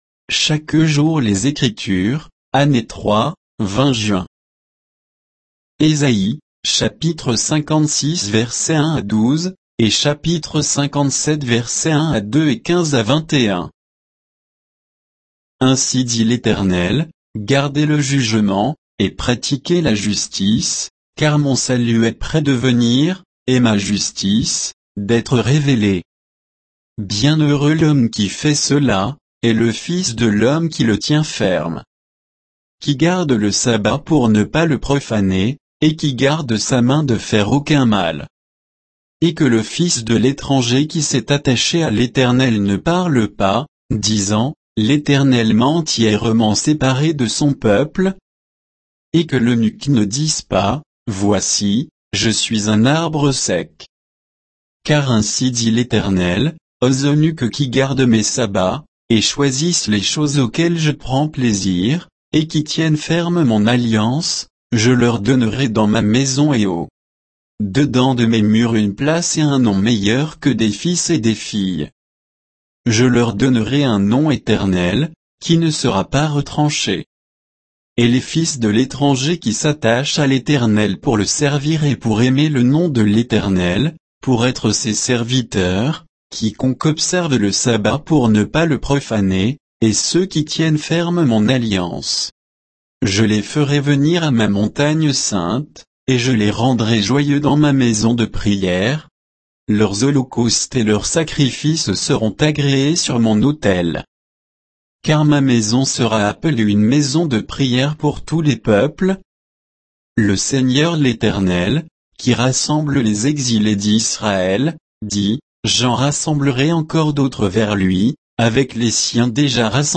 Méditation quoditienne de Chaque jour les Écritures sur Ésaïe 56, 1 à 12; 57, 1-2, 15-21